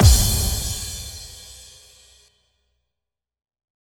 Big Drum Hit 19.wav